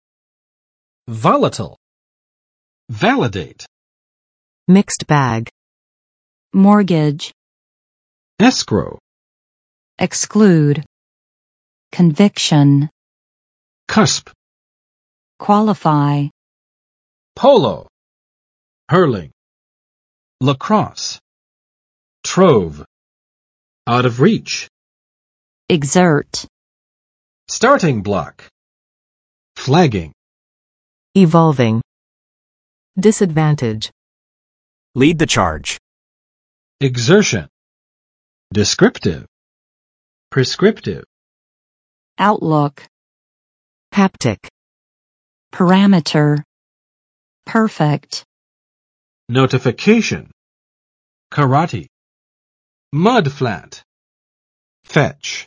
Vocabulary Test - January 24, 2022
[ˋvɑlət!] adj. 易变的；反复无常的
volatile.mp3